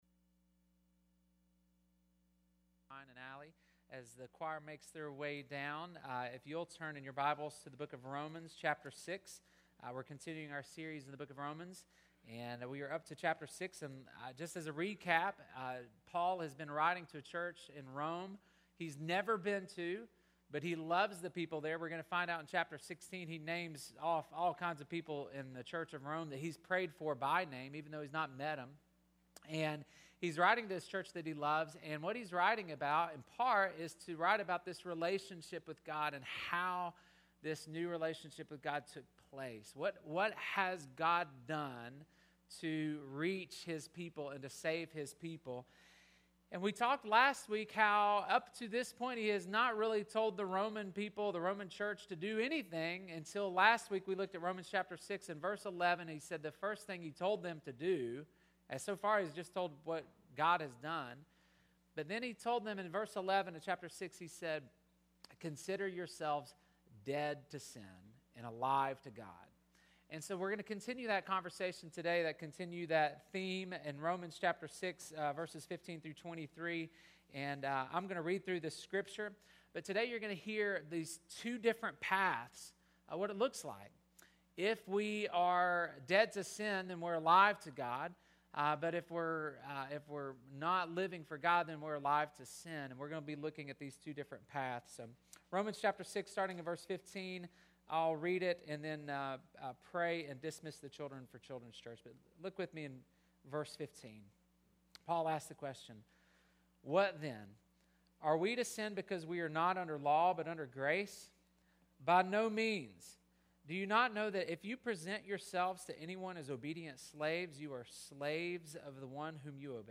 Everybody Serves Somebody July 14, 2019 Listen to sermon 1.